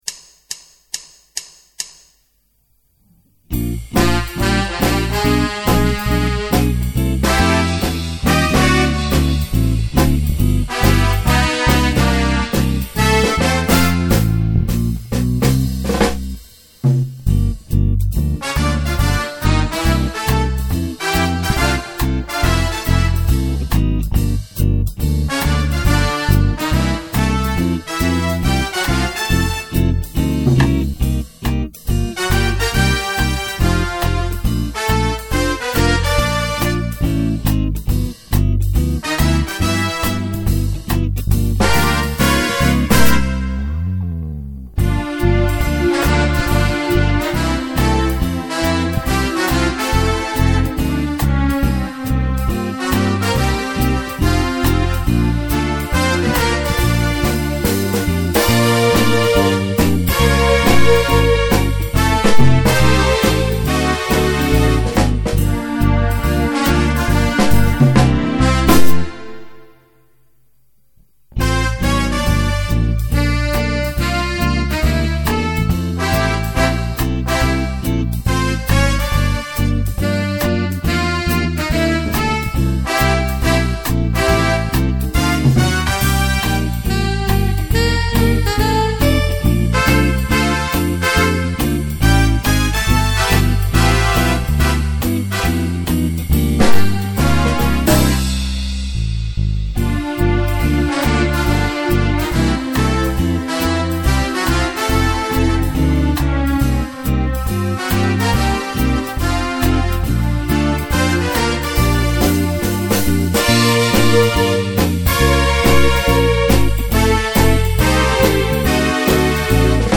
swing moderato
Swing. Clarinet, orchestra